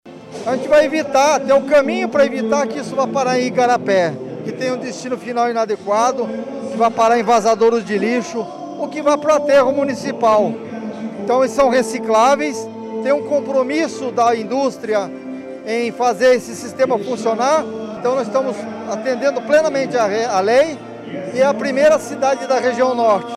Sonora-Antonio-Stroski-–-Secretario-Semmas.mp3